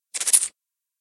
PixelPerfectionCE/assets/minecraft/sounds/mob/silverfish/hit1.ogg at mc116